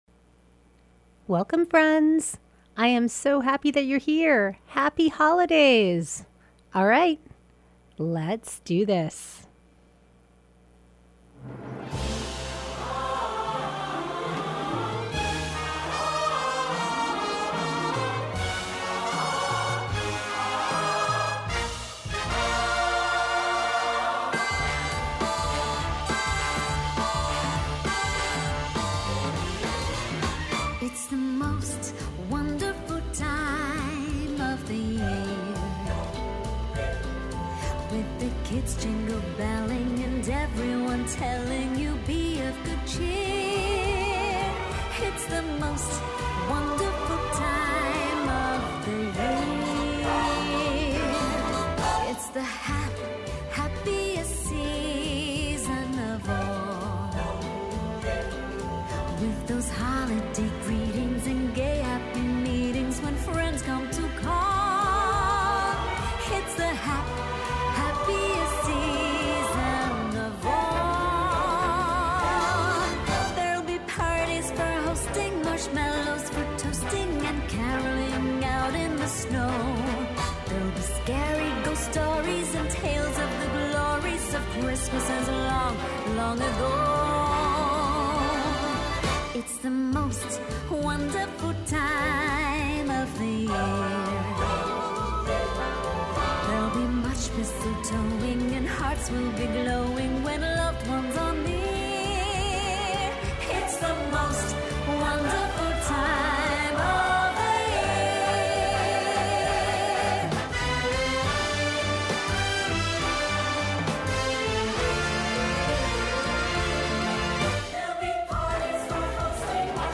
Broadcast every Thursday evening from 6:30 to 8pm on WTBR